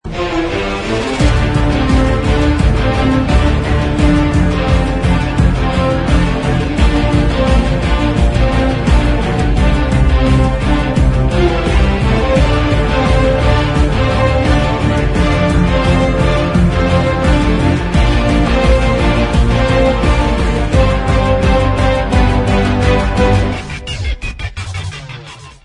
Cine y Televisión